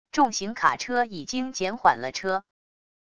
重型卡车已经减缓了车wav音频